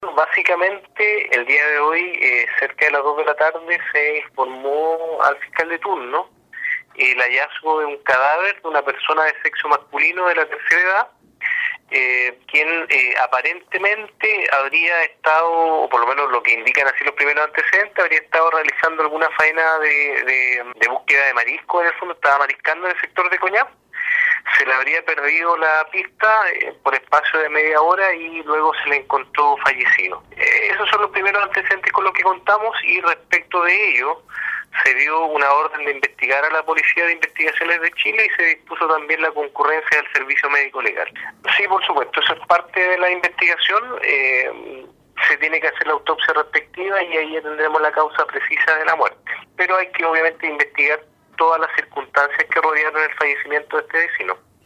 El Fiscal Cristian Mena, con jurisdicción en la comuna de Quinchao, confirmó el hallazgo del cadáver y el inicio de la indagatoria para poder determinar las causas que provocaron su fallecimiento.